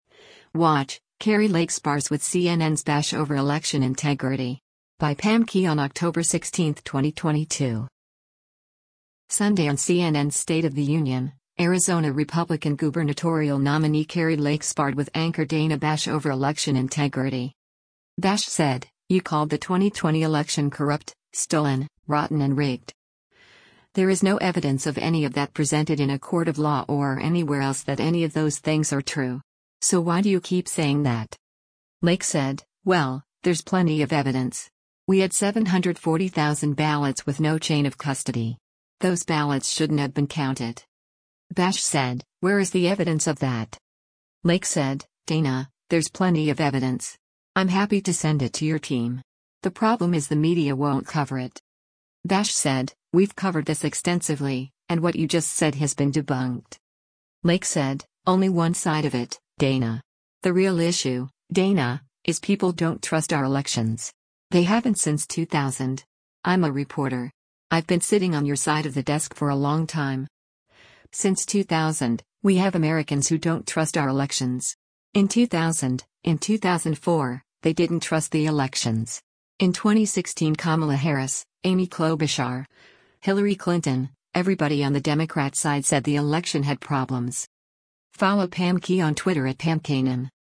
Sunday on CNN’s “State of the Union,” Arizona Republican gubernatorial nominee Kari Lake sparred with anchor Dana Bash over election integrity.